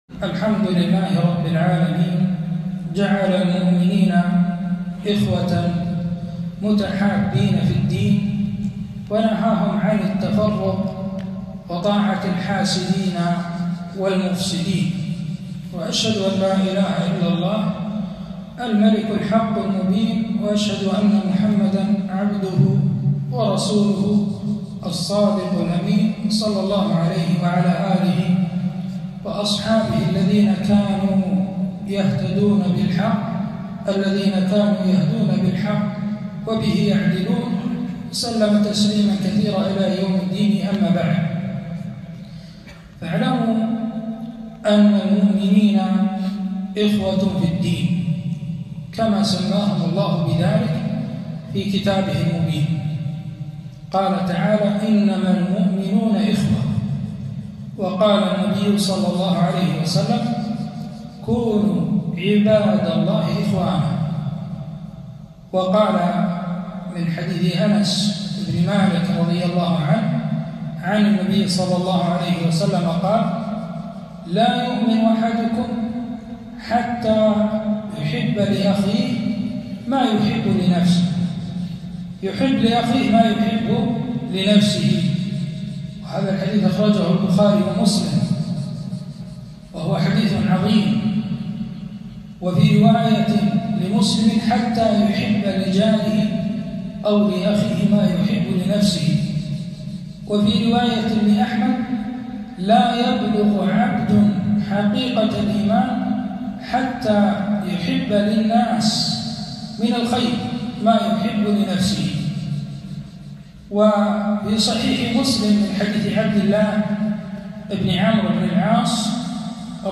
محاضرة - الأخوة في الدين ومستلزماتها